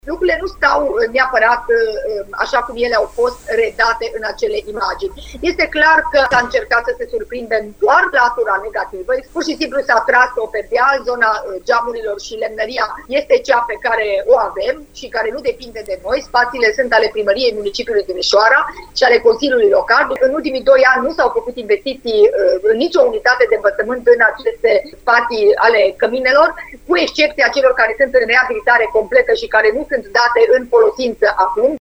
Inspector Școlar General, Aura Danielescu: